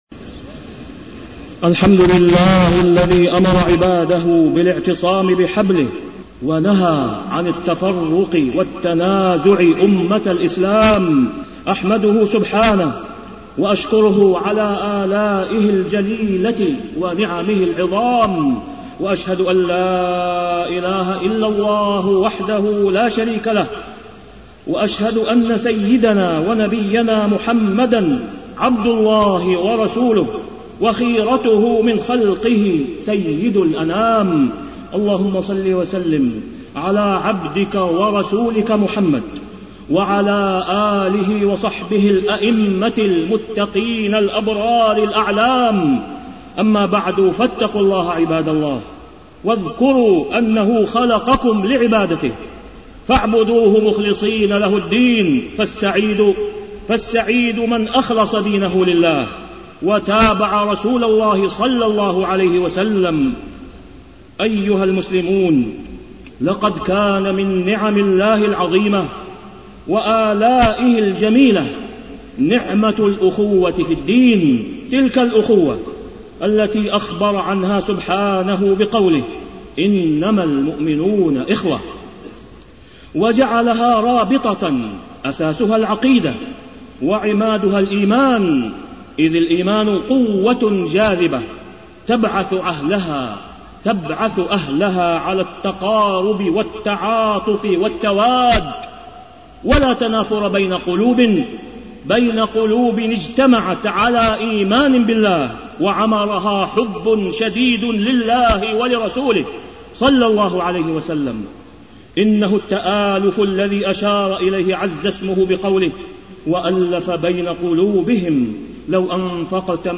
تاريخ النشر ٢٣ ذو القعدة ١٤٢٩ هـ المكان: المسجد الحرام الشيخ: فضيلة الشيخ د. أسامة بن عبدالله خياط فضيلة الشيخ د. أسامة بن عبدالله خياط الأخوة في الدين وأثرها على الأمة